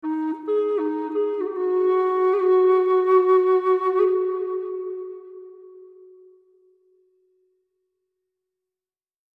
Hiệu ứng tiếng Sáo thổi (ngắn)
Thể loại: Hiệu ứng âm thanh
Description: Hiệu ứng tiếng sáo thổi (ngắn) là âm thanh du dương, ngân nga của sáo trúc, mang lại cảm giác nhẹ nhàng và thư thái cho người nghe. Giai điệu trầm bổng vang lên như hòa quyện cùng không gian rộng mở, gợi hình ảnh thiên nhiên yên bình và thoáng đãng. Âm sắc trong trẻo, mượt mà giúp tạo nên bầu không khí tĩnh lặng, phù hợp để lồng ghép vào các video cần sự lắng đọng, nhẹ nhàng...
Hieu-ung-tieng-sao-thoi-ngan-www_tiengdong_com.mp3